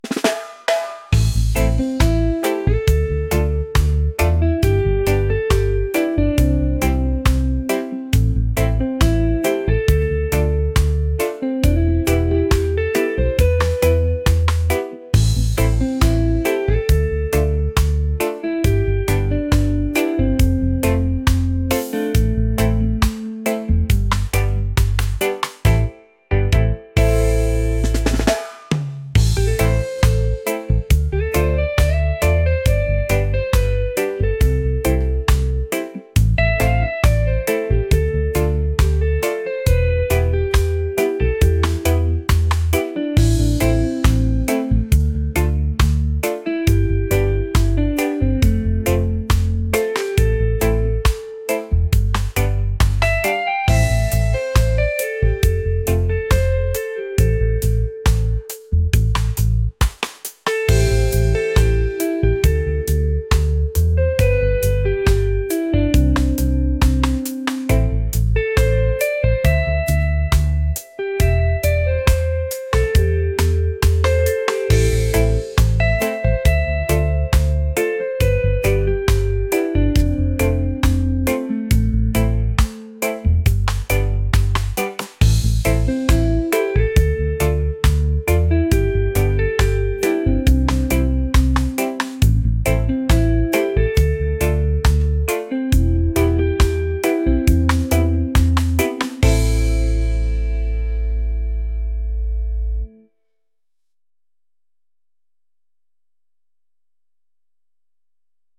laid-back | smooth | reggae